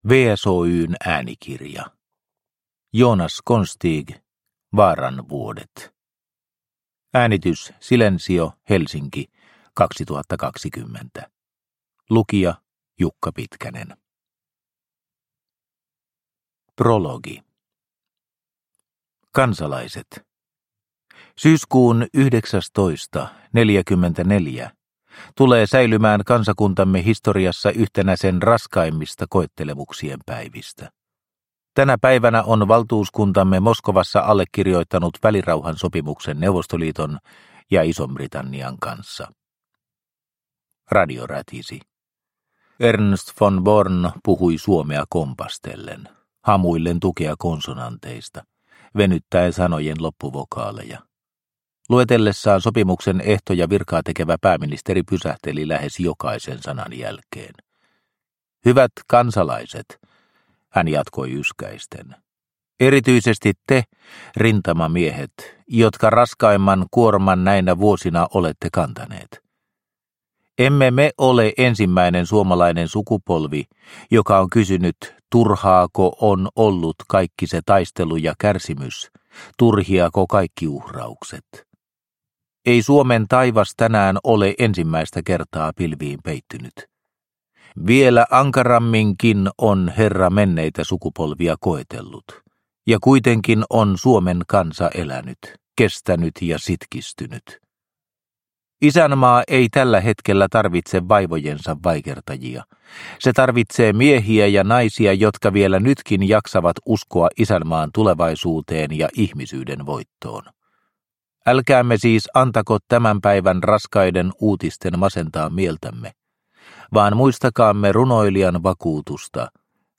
Vaaran vuodet – Ljudbok – Laddas ner